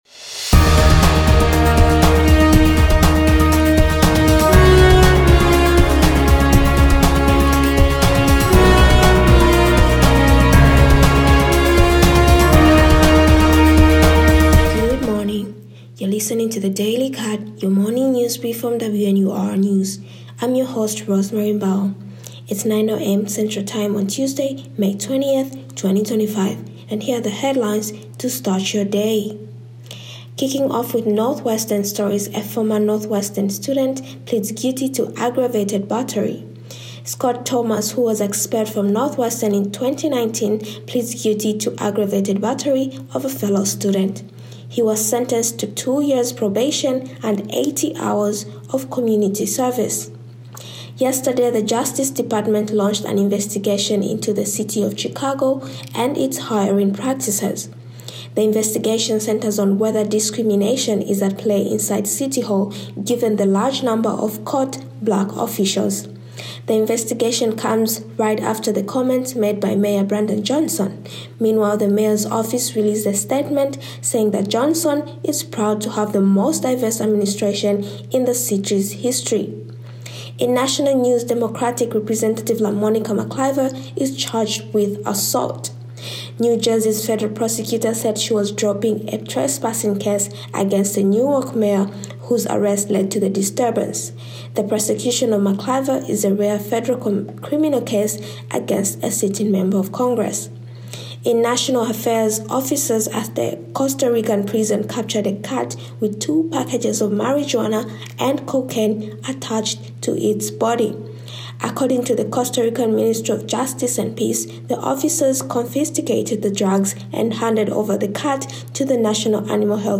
DATE:May 19, 2025 NU former student aggaravted battery case,DOJ investigation in Chicago hiring practices,LaMonica Mclver charged with assault, A cat captured with drugs on the body in Costa Rica. WNUR News broadcasts live at 6 pm CST on Mondays, Wednesdays, and Fridays on WNUR 89.3 FM.